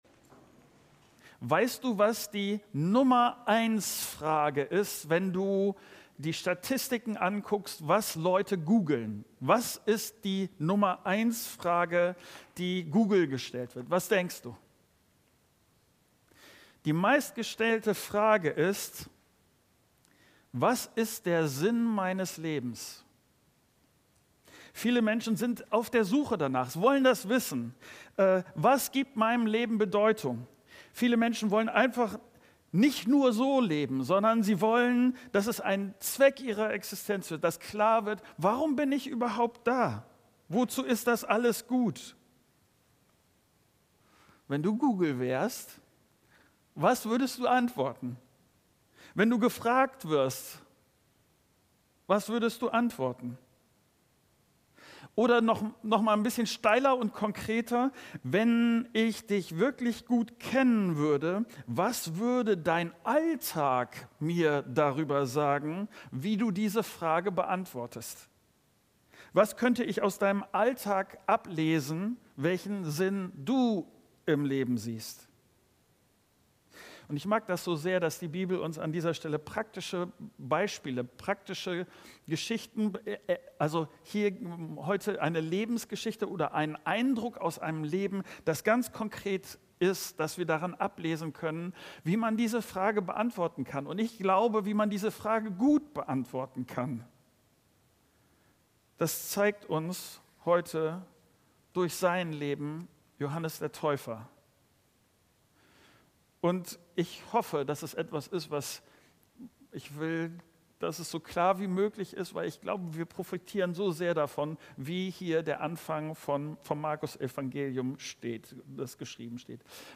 08.03.2026 - Wer meinem Leben Sinn gibt ~ Predigten der Christus-Gemeinde | Audio-Podcast Podcast